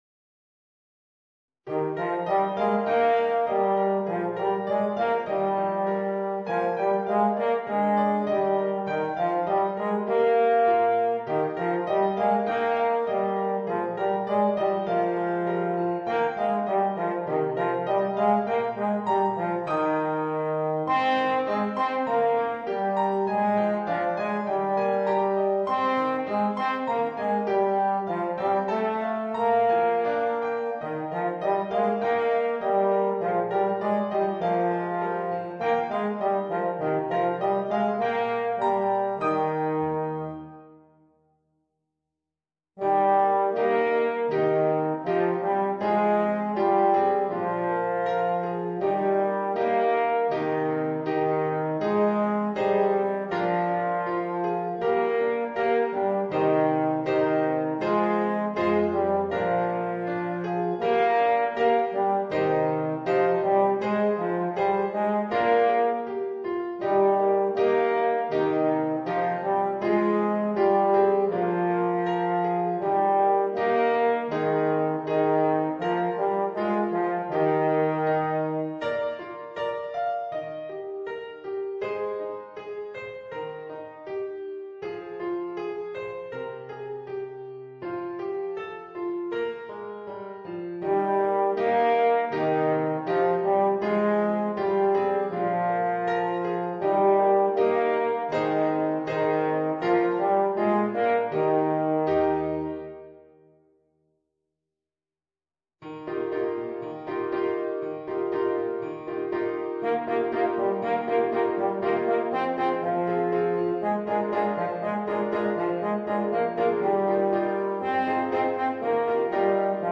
Gattung: für Horn und Klavier